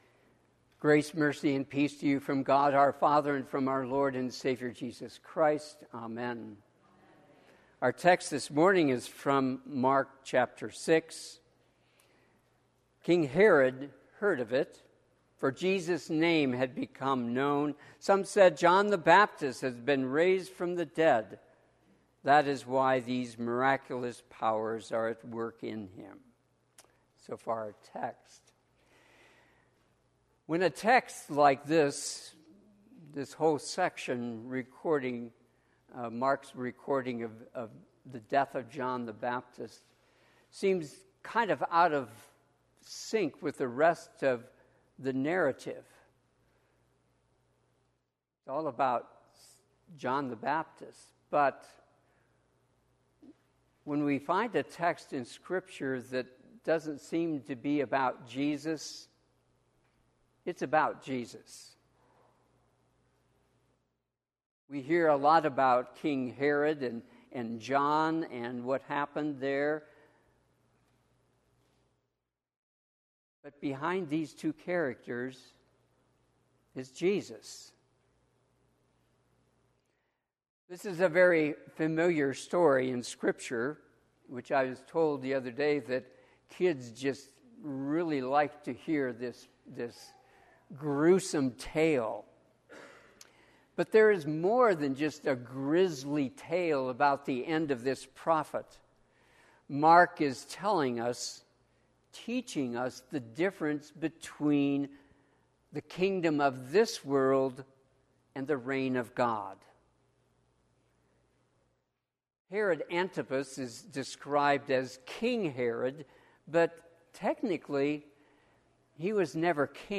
Sermon - 7/14/2024 - Wheat Ridge Lutheran Church, Wheat Ridge, Colorado
Eighth Sunday after Pentecost